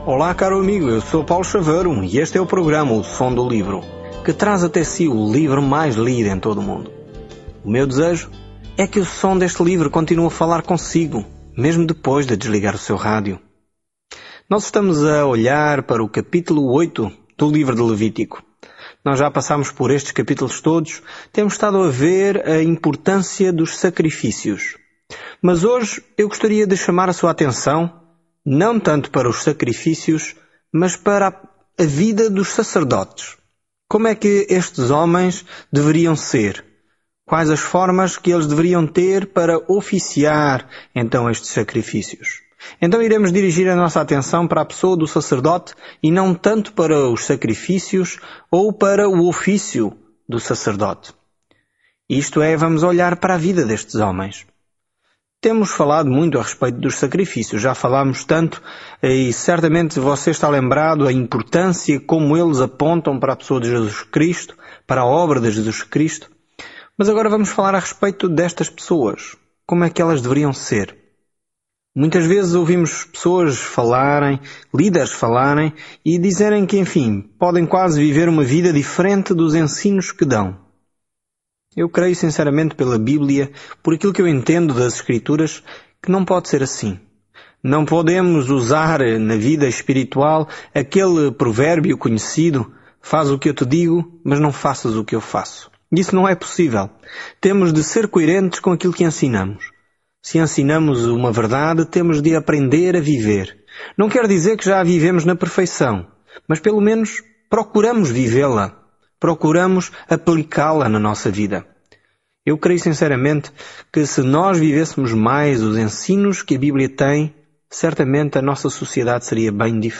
Em adoração, sacrifício e reverência, Levítico responde a essa pergunta para o antigo Israel. Viaje diariamente por Levítico enquanto ouve o estudo em áudio e lê versículos selecionados da palavra de Deus.